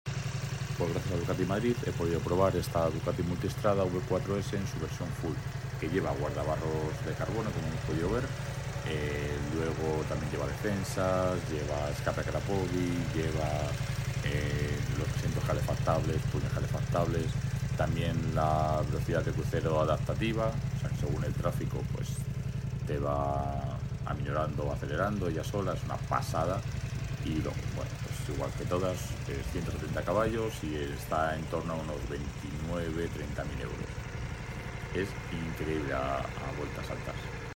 Ducati Multistrada V4S Full 170cv